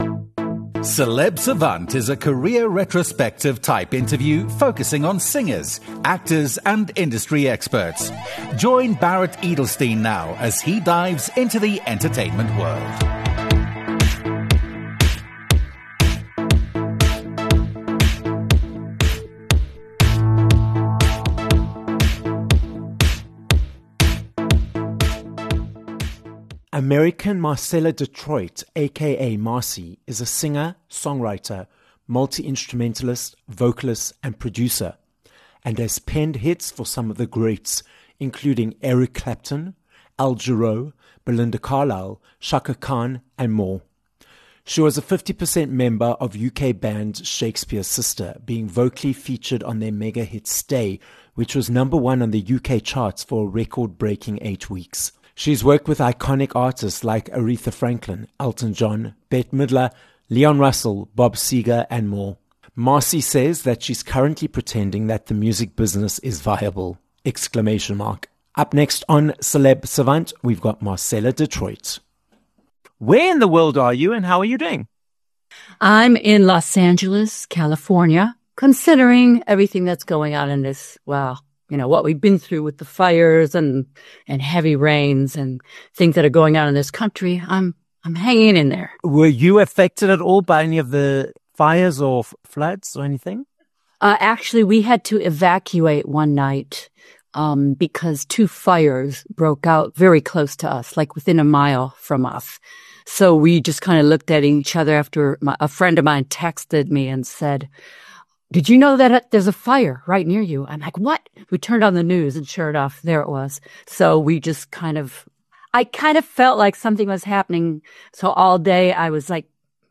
Marcella Detroit - an American singer, songwriter, multi-instrumentalist, and producer - joins us on this episode of Celeb Savant. Marcella tells us about her multi-decade journey in the music industry, which includes collaborating with Bob Seger, Eric Clapton, Leon Russell, amongst others, the challenge of shaking the image of being a background singer, the story behind Shakespears Sister and more.